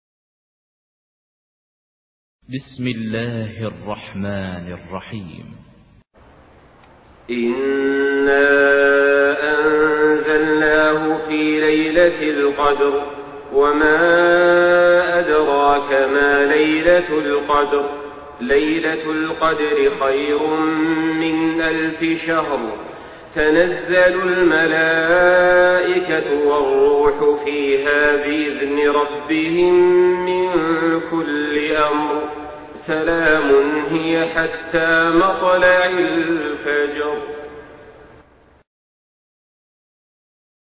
Surah Al-Qadr MP3 by Saleh Al-Talib in Hafs An Asim narration.
Murattal